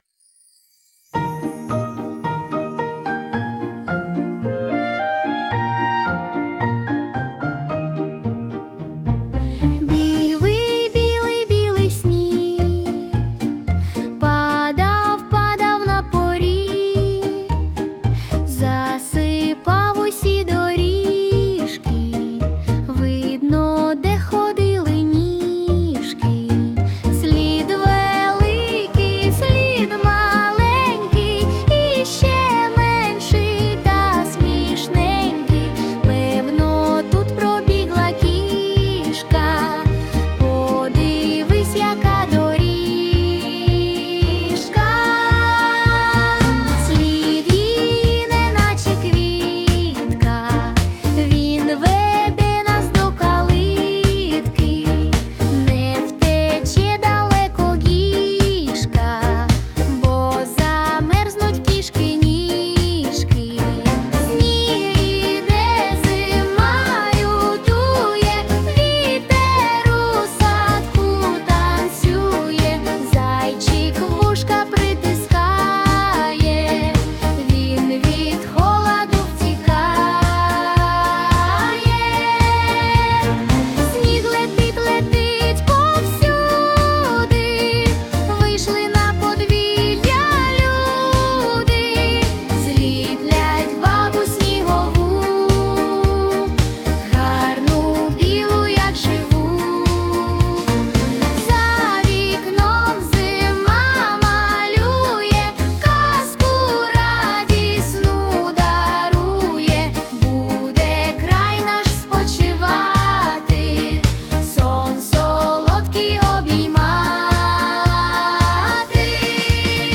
🎵 Жанр: Дитяча поп-музика / Хор